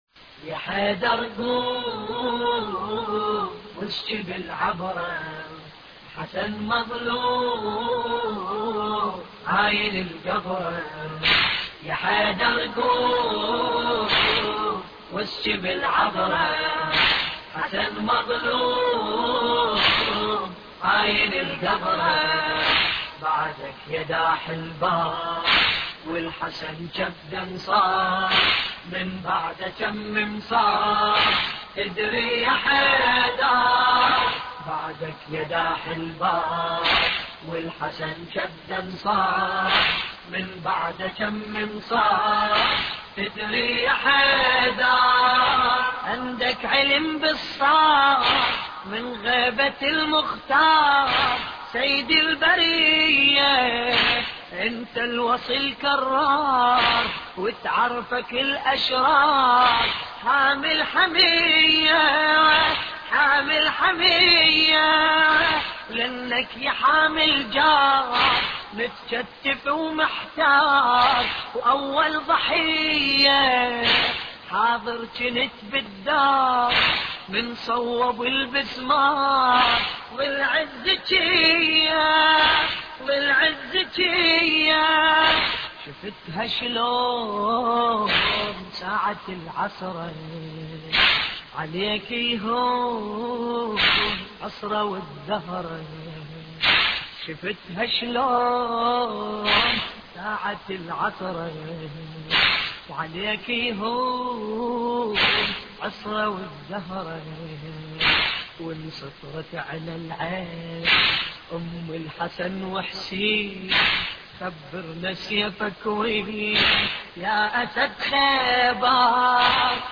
مراثي الامام الحسن (ع)